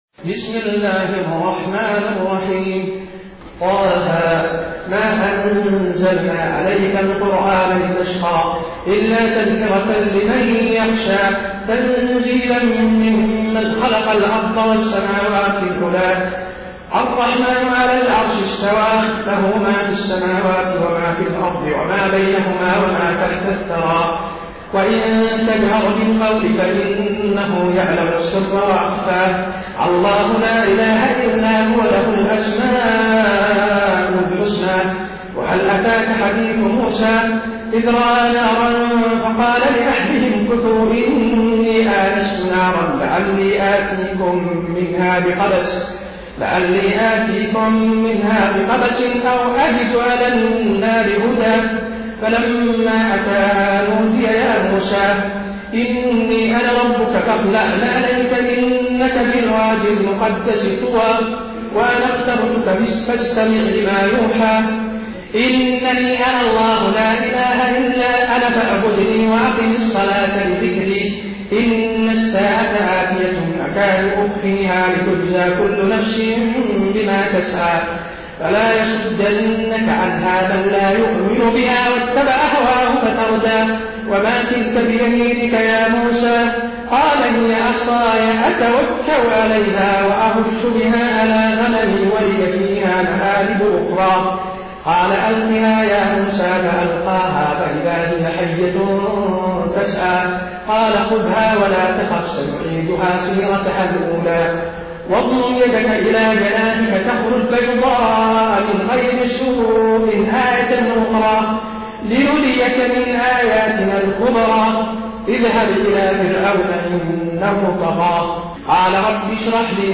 Quran recitations
Harameen Madinah taraweeh-1433-madina